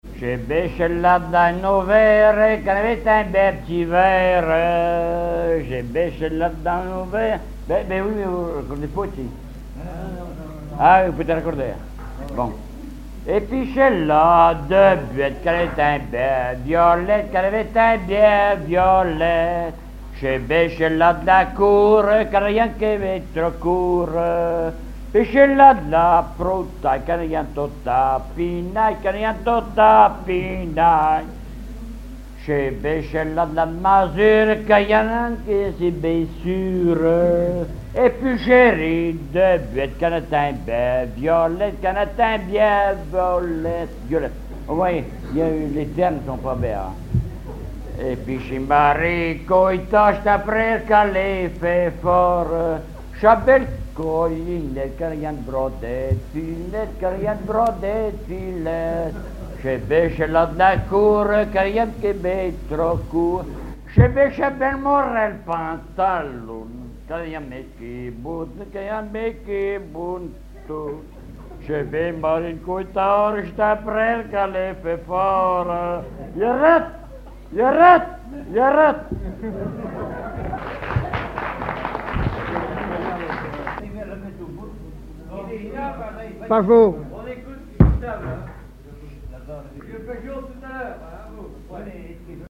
Genre énumérative
à la salle d'Orouët
Pièce musicale inédite